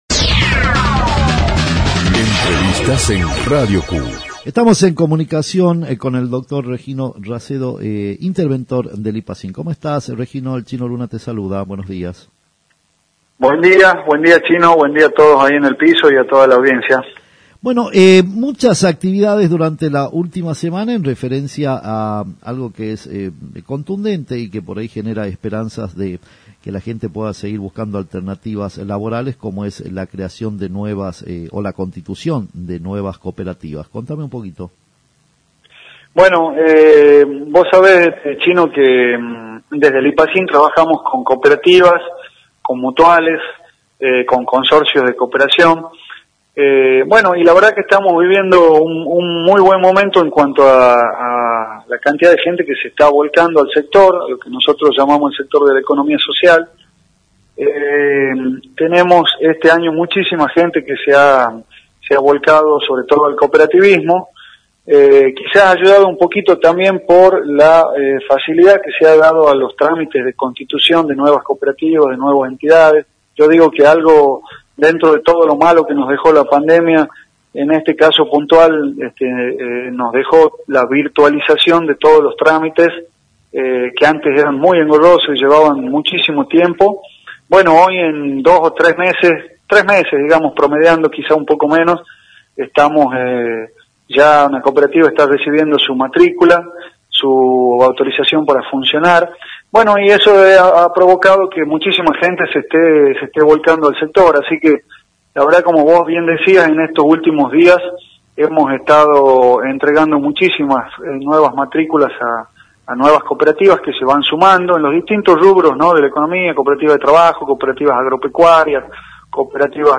Regino Racedo Titular del Instituto Provincial de Acción Cooperativa y Mutual de Tucumán